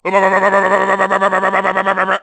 Line of Klump in Donkey Kong: Barrel Blast.
Klump_(electrocuted).oga.mp3